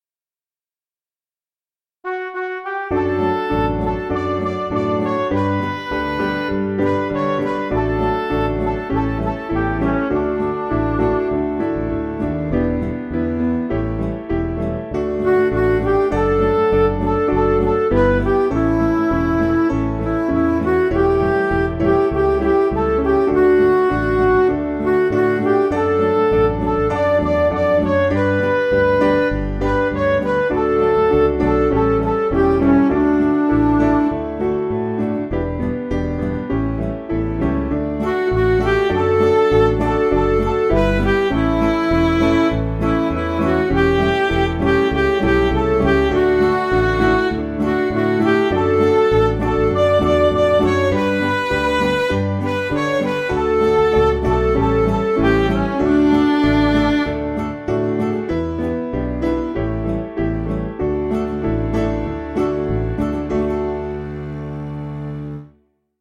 Liturgical Music
Piano & Instrumental
Midi